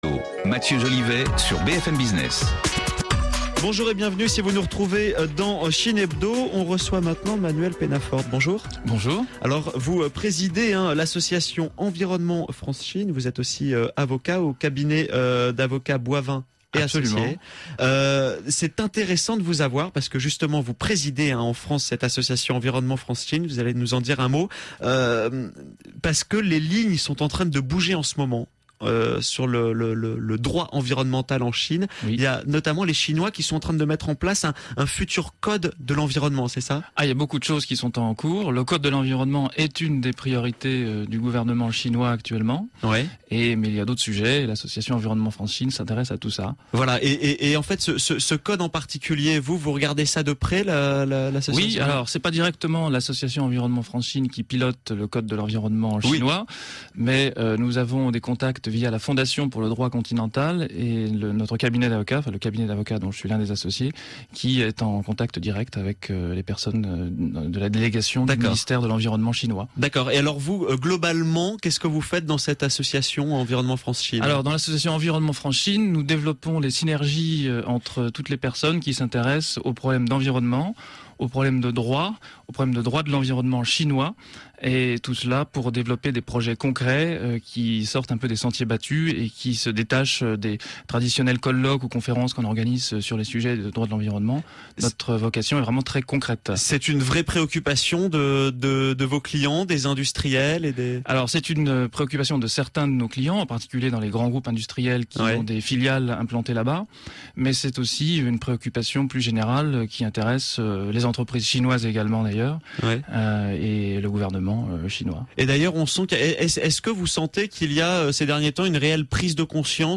a été interrogé sur la station BFM Radio lors de l’émission Chine hebdo pour parler des activités de l’association, notamment en cette période de renforcement du droit de l’environnement chinois.